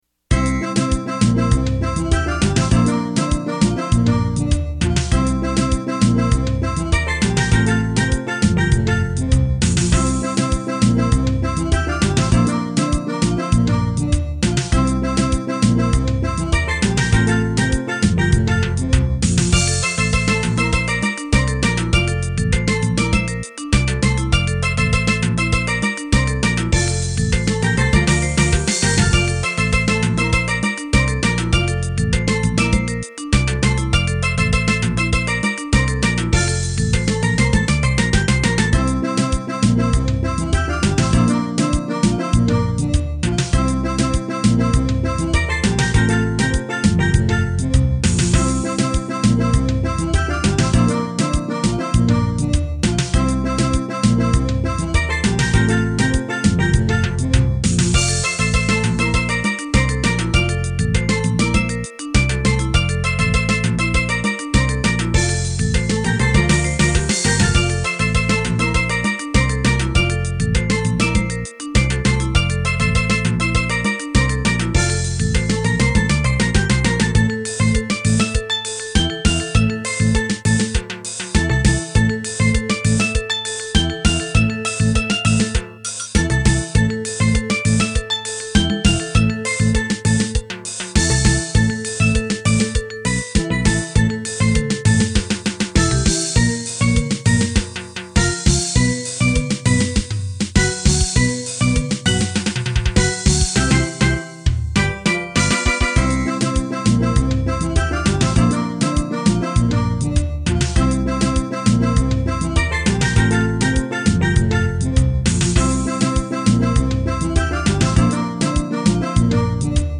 Genre: Steel Drum Band
Lead Pan
Cello
Vibraphone
Electric Bass
Drum set
Assorted Percussion